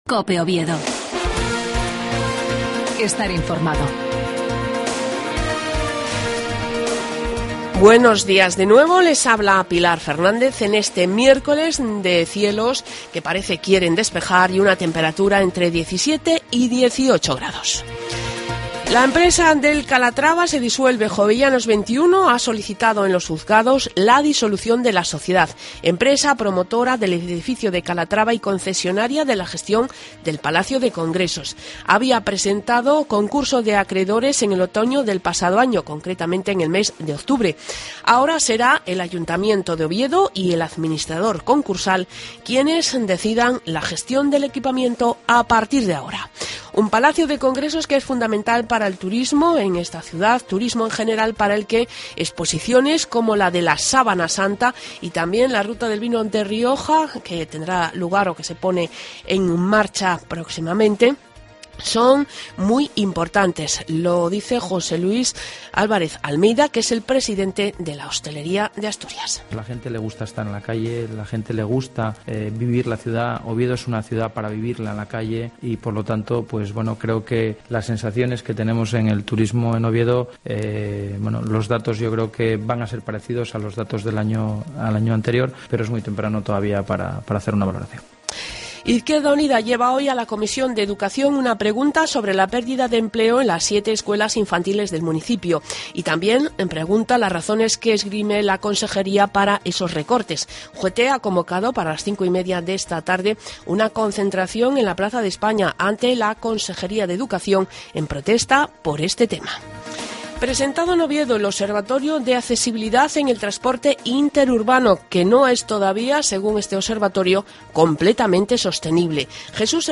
AUDIO: LAS NOTICIAS DE OVIEDO A PRIMERA HORA DE LA MAÑANA.